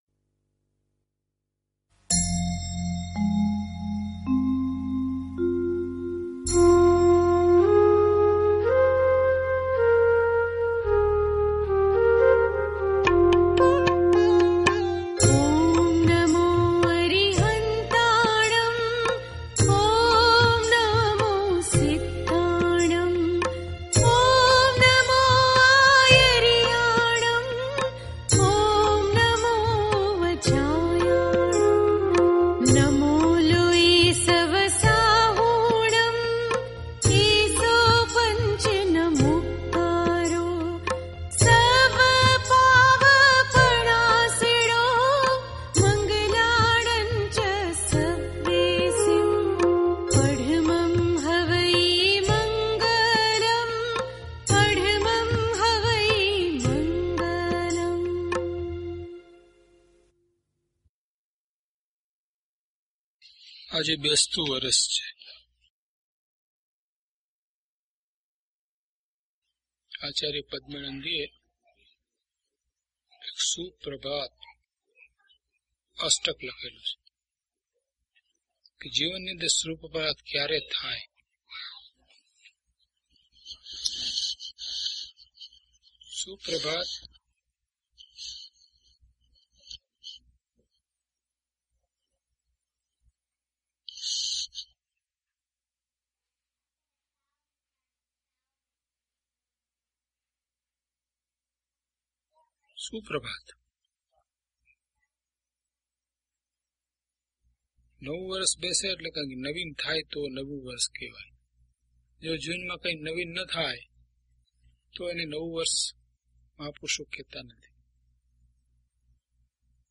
Audio title: DHP008 Apurva Avasar Gatha 9 to 12 - Pravachan.mp3